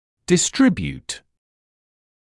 [dɪ’strɪbjuːt][ди’стрибьюːт]распределять